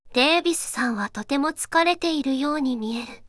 voicevox-voice-corpus / ita-corpus /四国めたん_セクシー /EMOTION100_003.wav